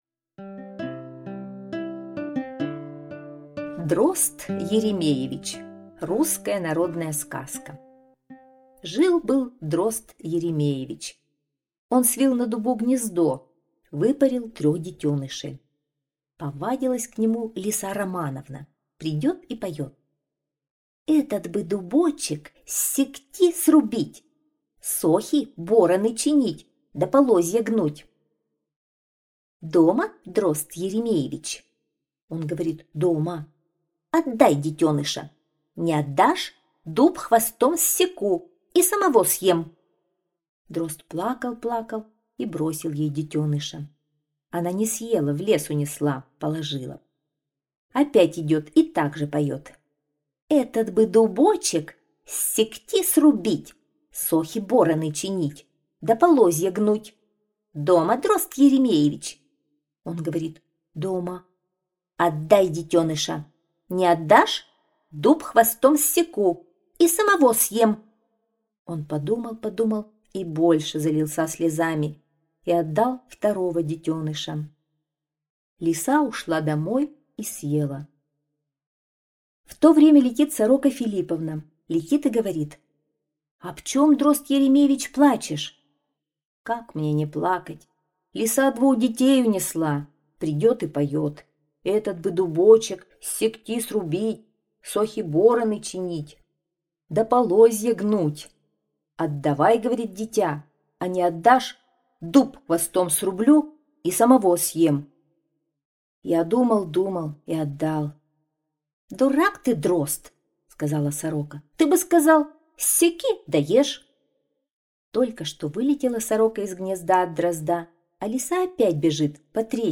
Дрозд Еремеевич -русская народная аудиосказка. Как Сорока Филипповна помогла Дрозду Еремеевичу спасти последнего птенца.